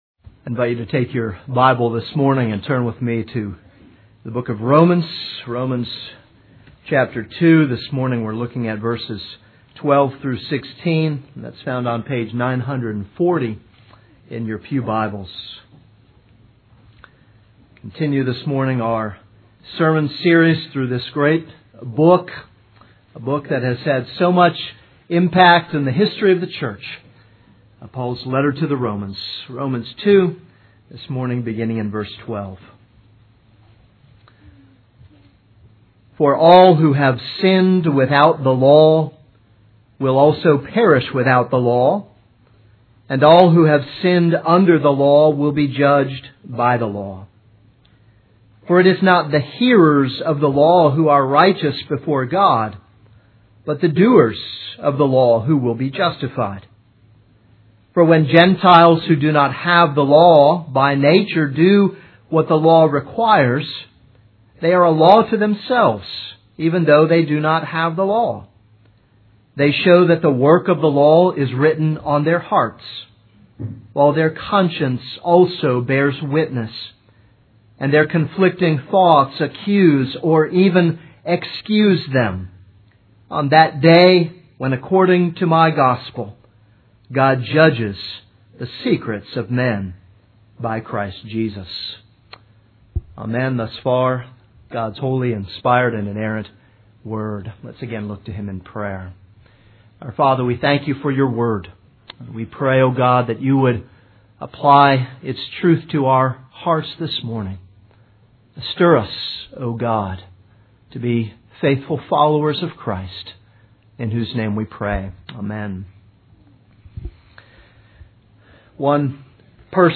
This is a sermon on Romans 2:12-16.